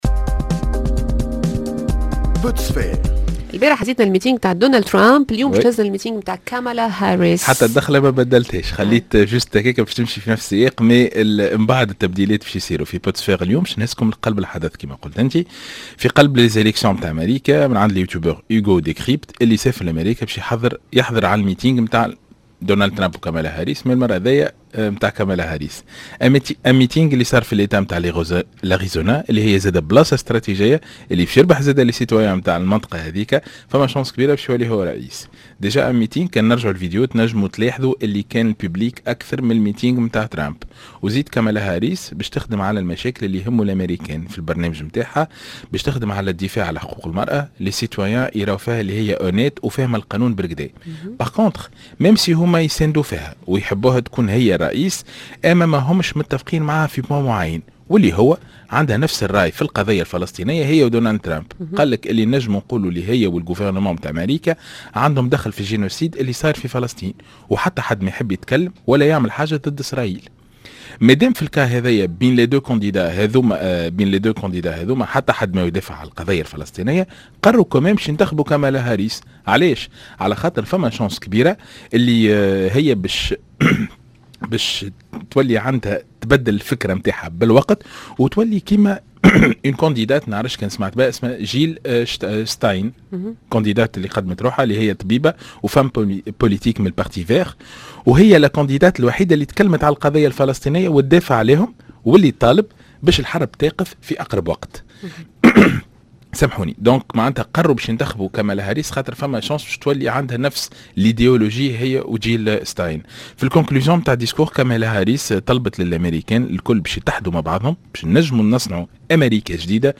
Au cœur du meeting de Kamala Harris.